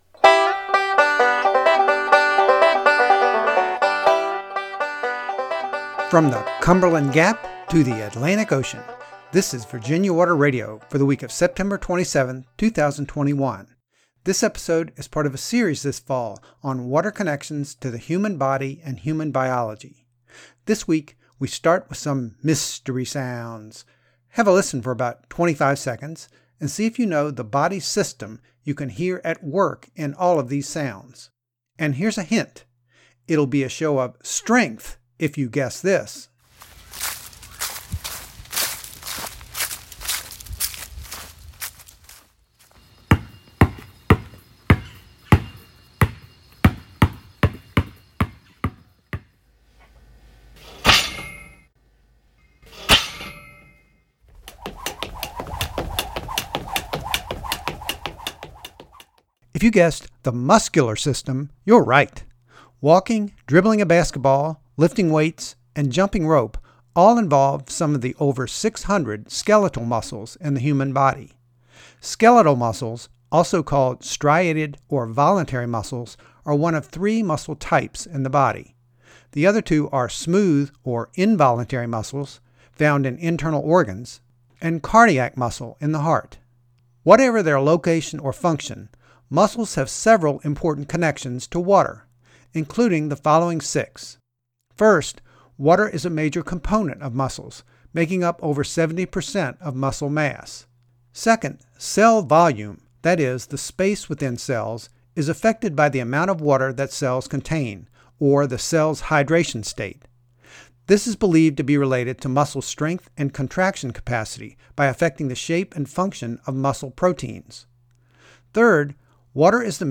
The sounds heard in this episode were recorded by Virginia Water Radio in Blacksburg, Va., on September 23, 2021.